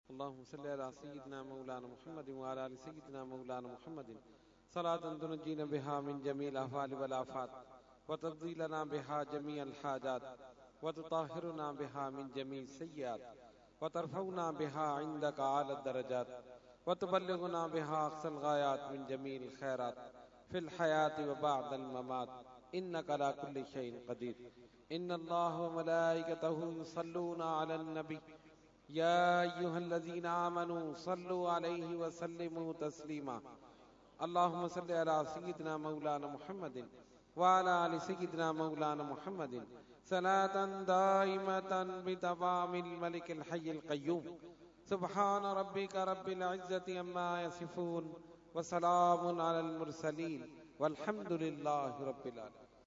Dua – Urs Qutbe Rabbani 2016 Day 2 – Dargah Alia Ashrafia Karachi Pakistan